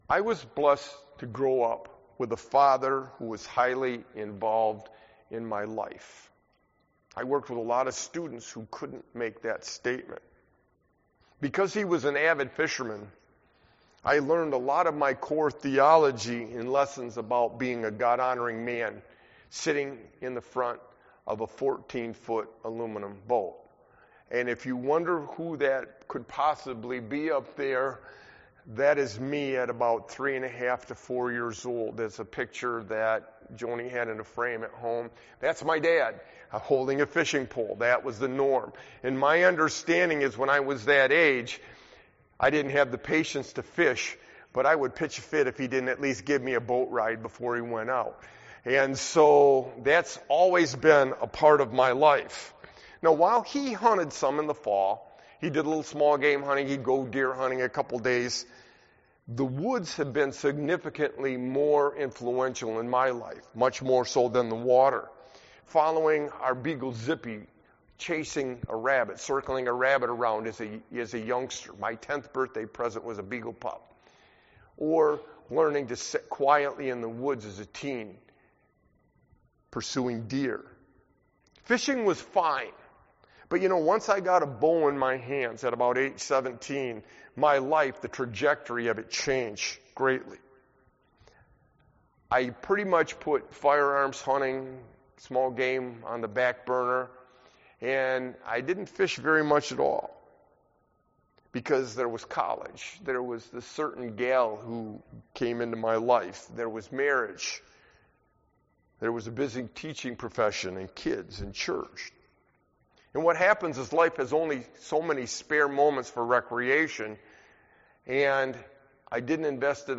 Luke 5:1-11; The first of a short summer sermon series on biblical fishing.